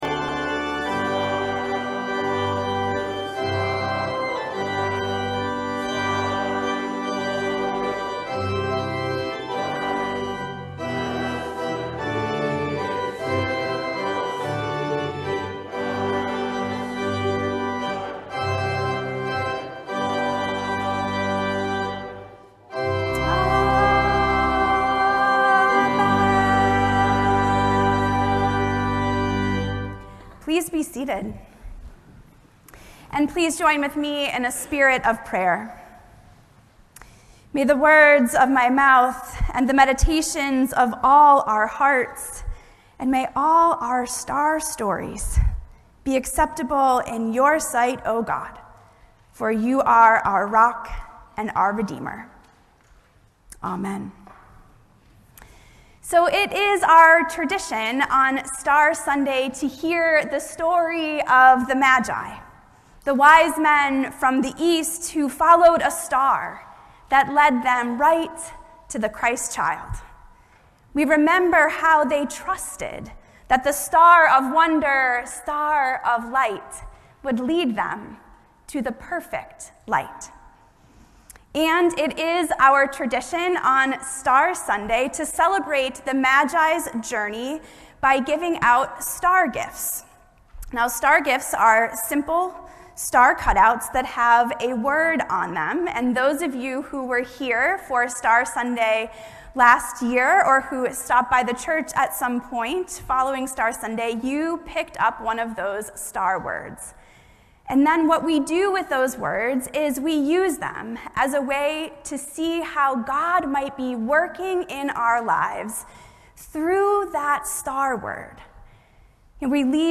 Sermons - Old South Union Church – Weymouth